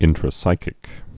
(ĭntrə-sīkĭk)